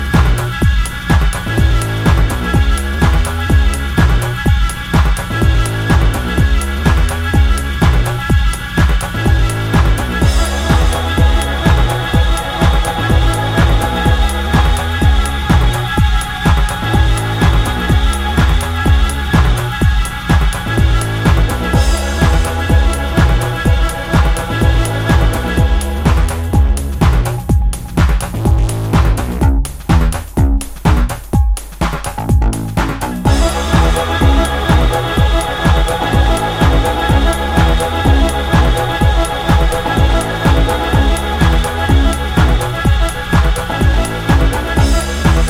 とありましたが、確かにそんなシチュエーションがバッチリハマるドラッギーな強力ウェポン！